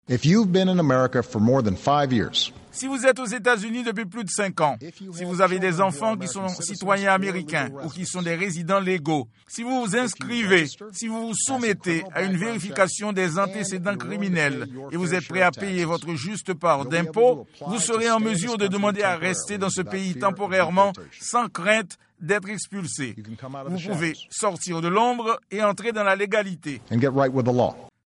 Le président s'adressant à la nation jeudi soir
Extrait du discours du président Obama